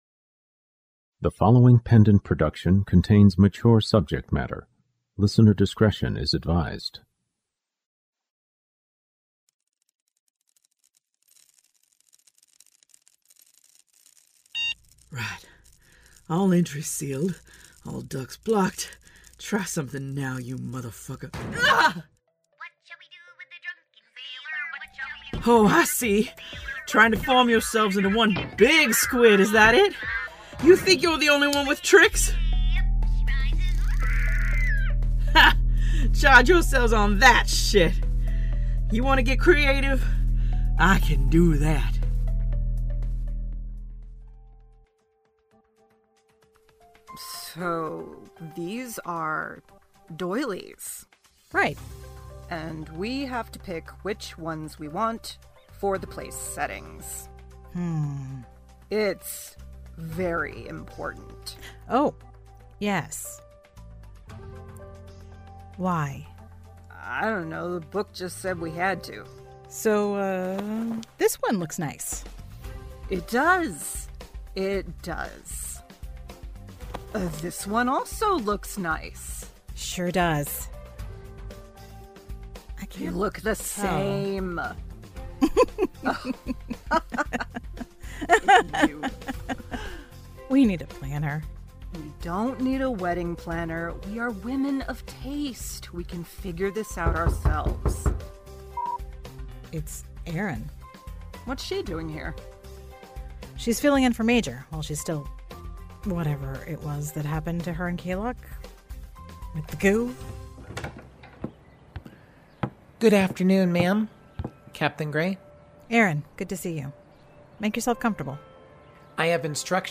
"The Kingery" is a full-cast, ongoing serial sci-fi crime drama.